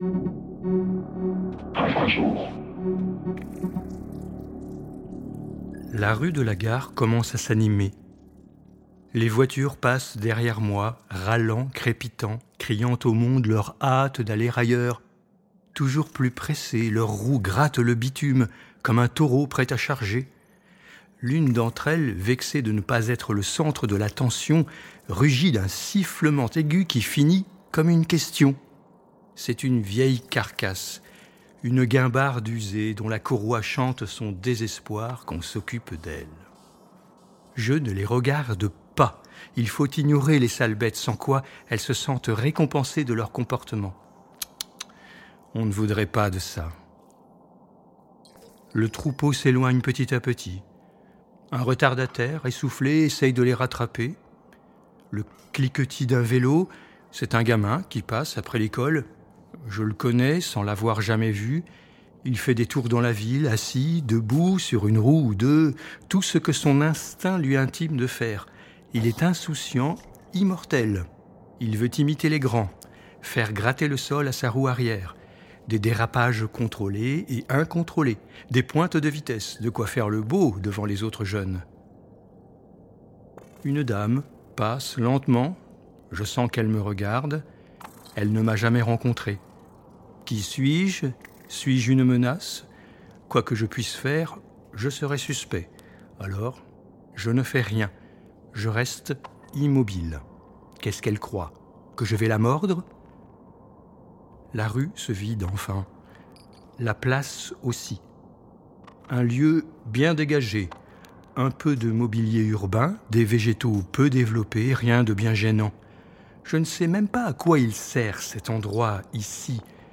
Musiques et effets sonores